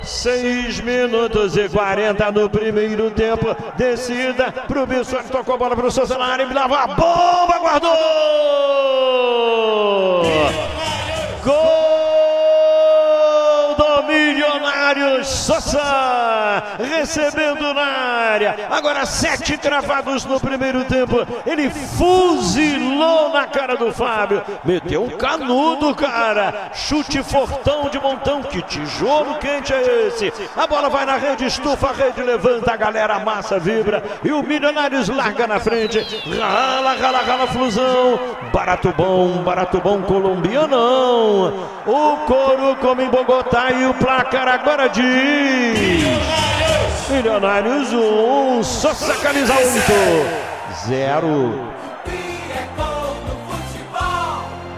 David Braz e Cano marcaram nos 2 a 1, em Bogotá, na Colômbia